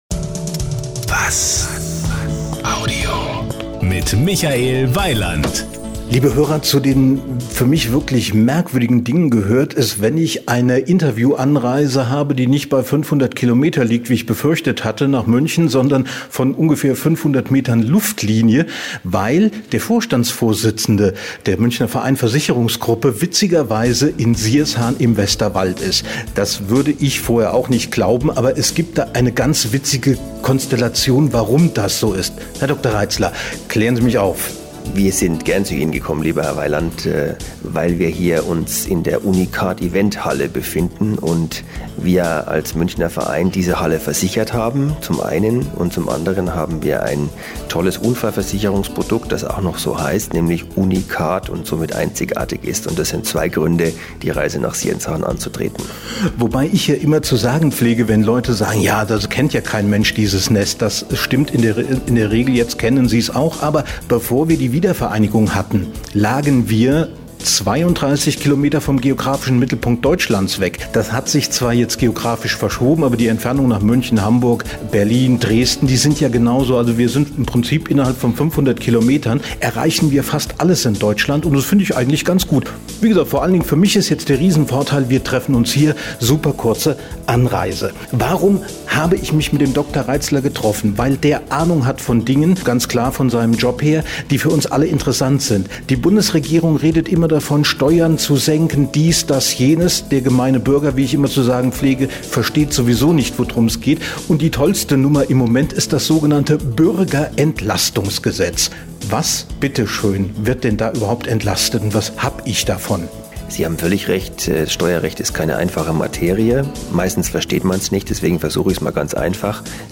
Hier gibt es das komplette Interview Länge: 14:30 minDer Radiobeitrag zum Thema Länge 2:48 min Seit Anfang Januar ist das Bürgerentlastungsgesetz in Kraft.